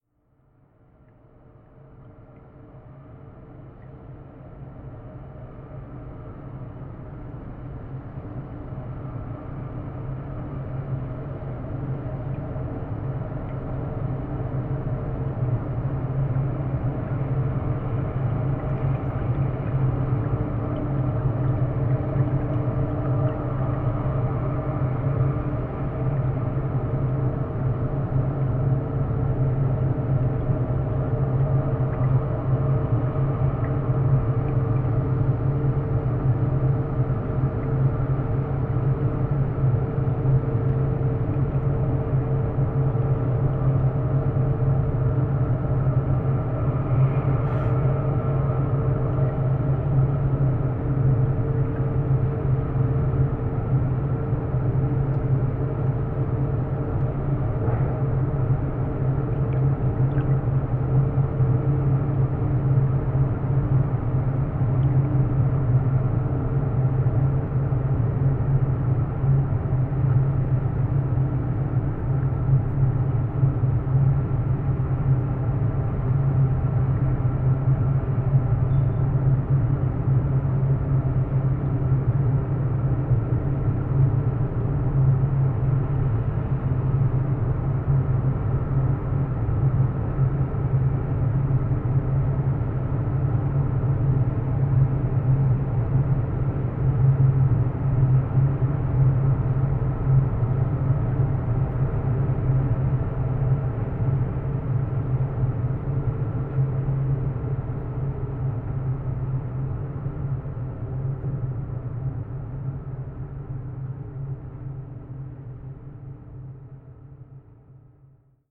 Die „Rhein-Klanglandschaft“ klingt nicht nur nach dem Wasser, welches fließt, gurgelt oder träge vor sich hin dümpelt.
Im Vordergrund ihrer künstlerischen Praxis steht die unbearbeitete Klang- und Bildaufnahme einer bestimmten Situation.
Field Recording Series by Gruenrekorder